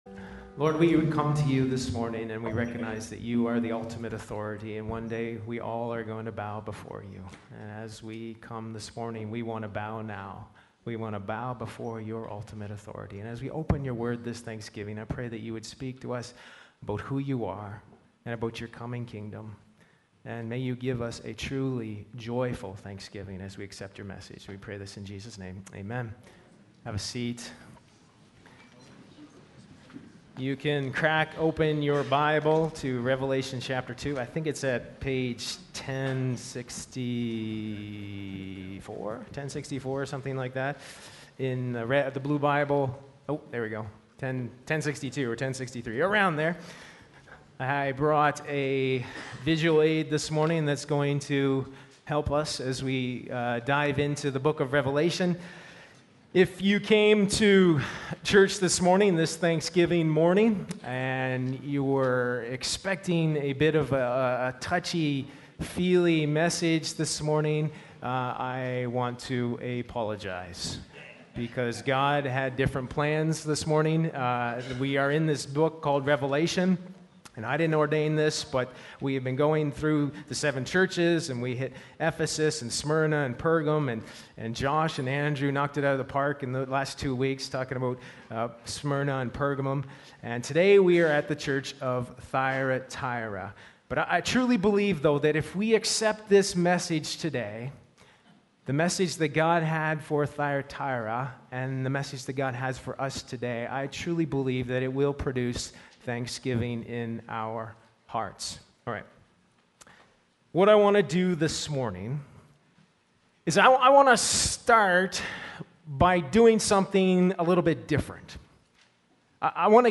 Sermons | Sturgeon Alliance Church